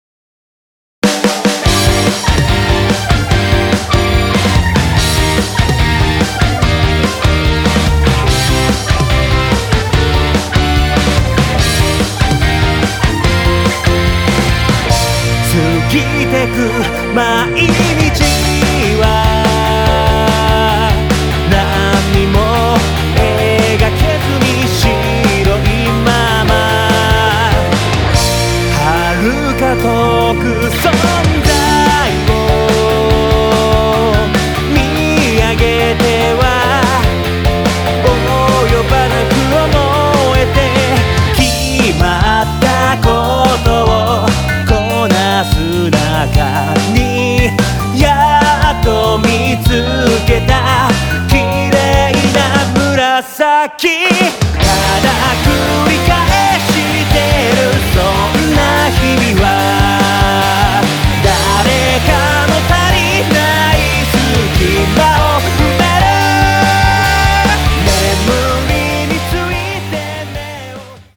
クロスフェードデモ
ギター炸裂・ベース爆裂・ドラム激烈のロックアレンジから極上バラードまで、